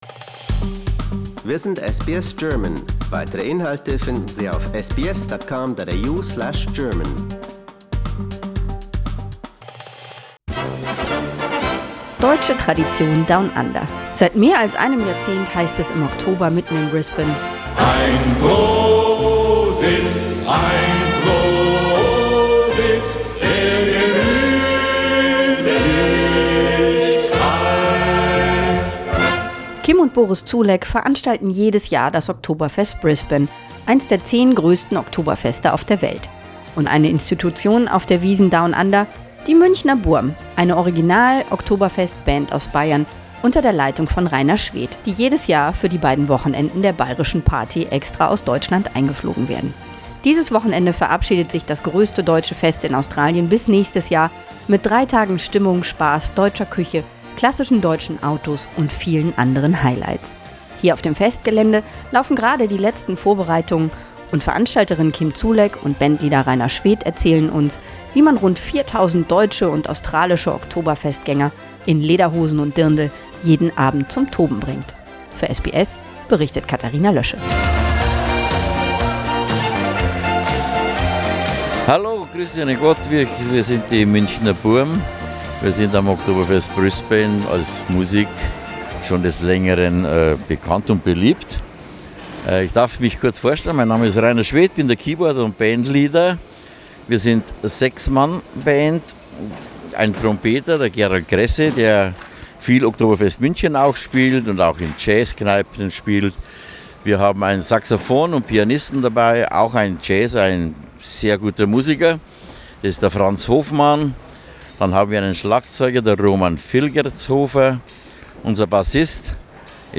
This weekend, the biggest German festival in Australia says goodbye until next year with 3 days of fun, German cuisine, classic German cars and many more highlights! Here at the festival grounds, the final preparations are in progress